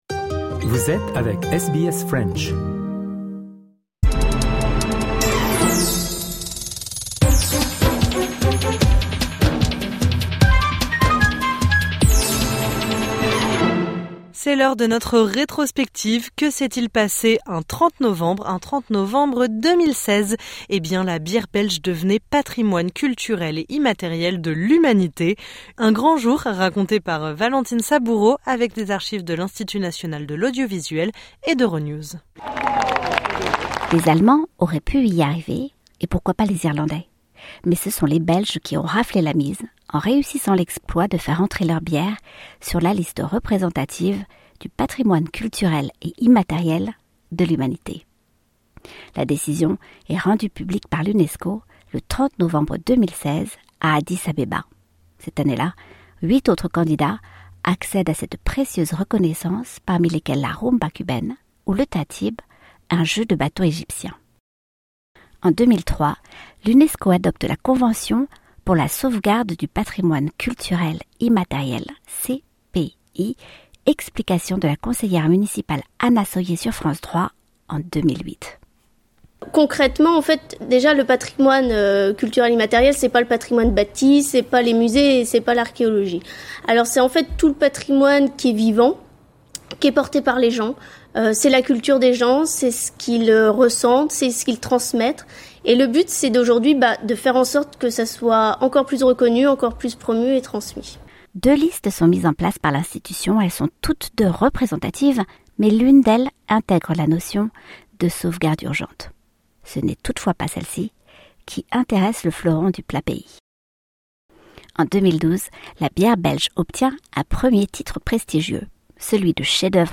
Un grand jour, raconté avec les archives de l’Institut national de l’audiovisuel et d’Euronews.